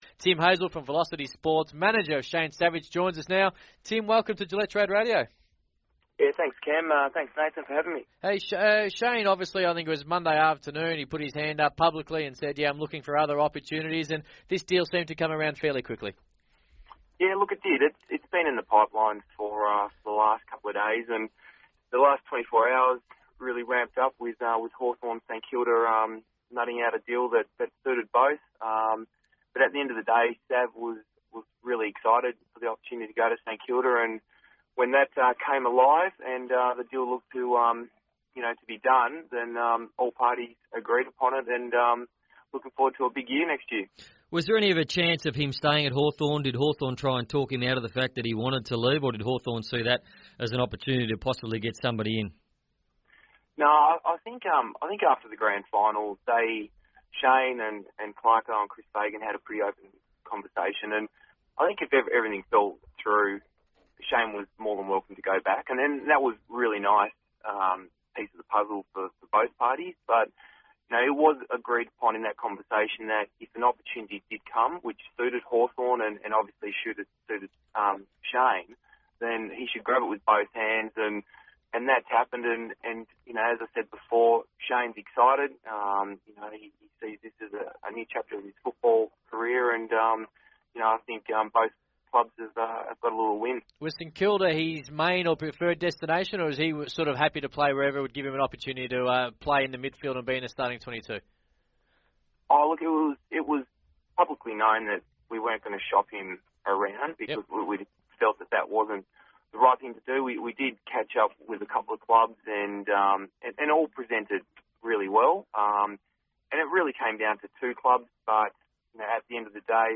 speaks to Gillette Trade Radio